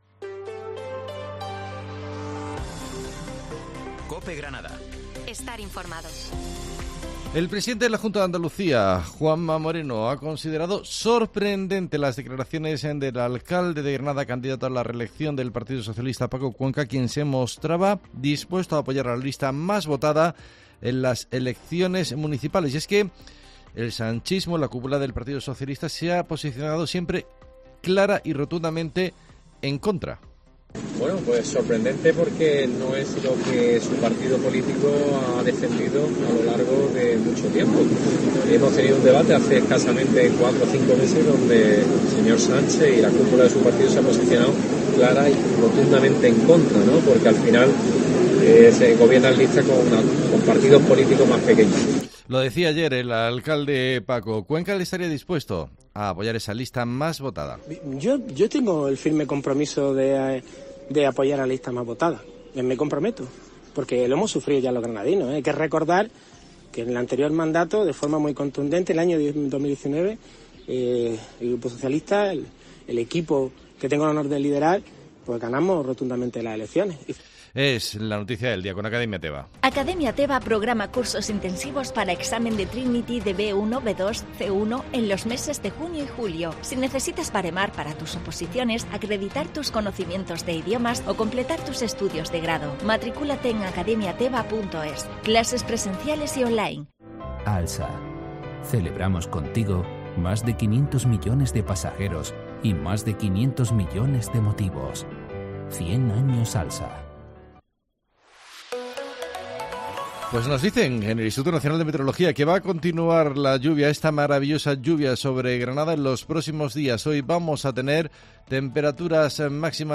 Herrera en Cope Granada, Informativo 23 de mayo